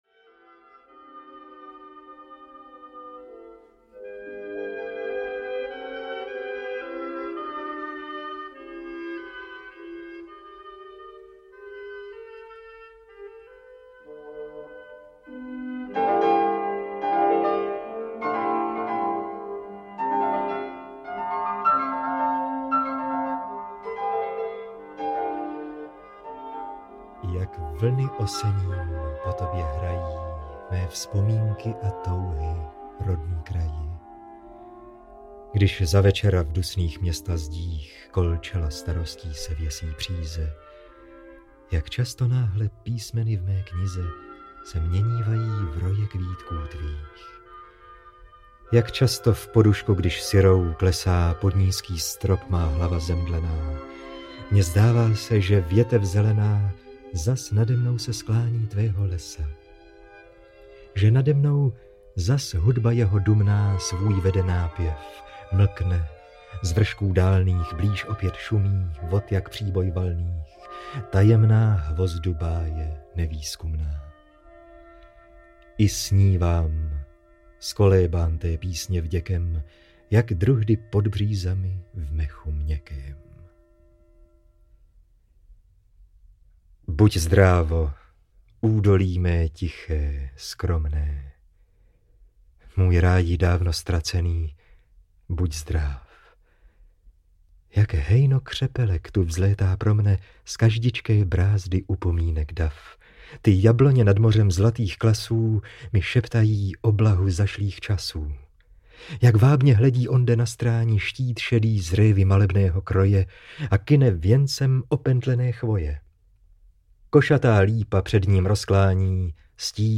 Ve stínu lípy audiokniha